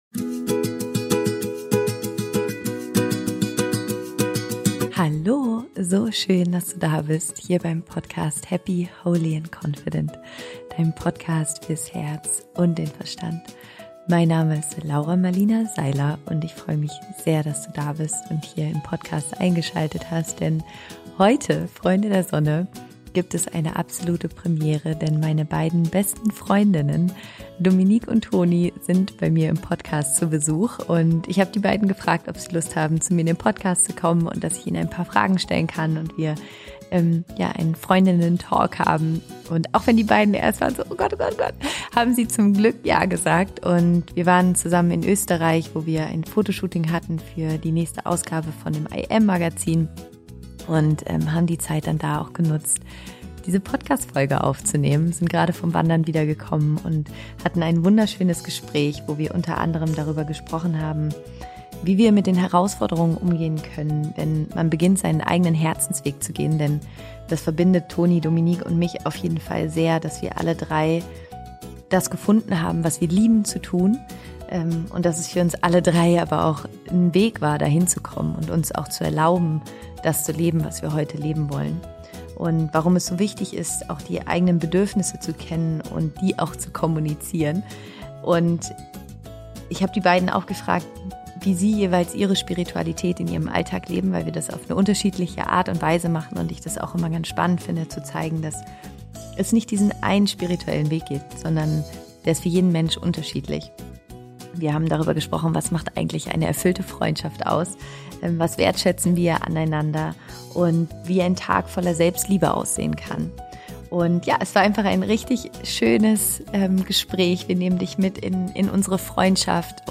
Meine besten Freundinnen im Podcast zum Gespräch - Interview Special